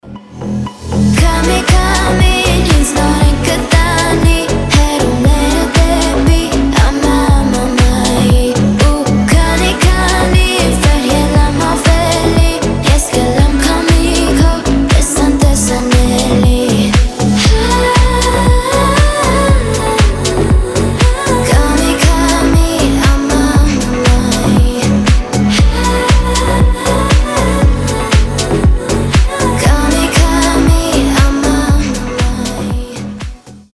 • Качество: 320, Stereo
детский голос